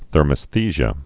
(thûrmĭs-thēzhə)